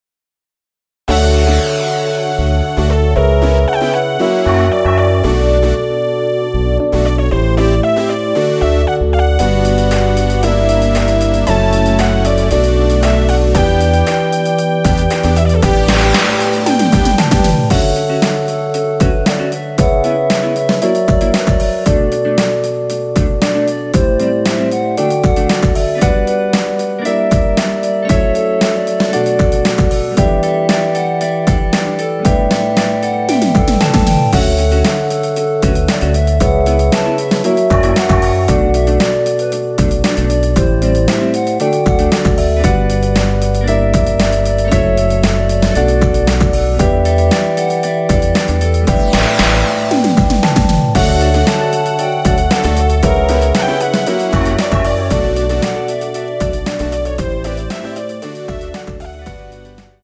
원키에서(+3)올린 멜로디 포함된 MR입니다.
앞부분30초, 뒷부분30초씩 편집해서 올려 드리고 있습니다.